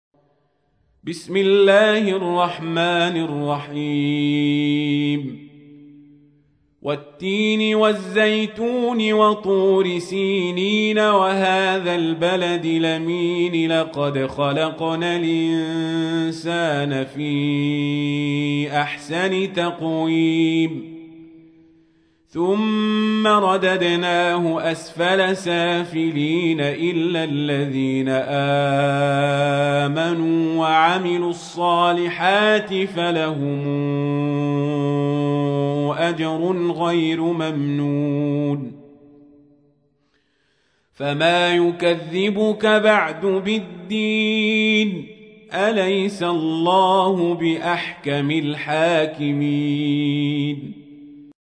تحميل : 95. سورة التين / القارئ القزابري / القرآن الكريم / موقع يا حسين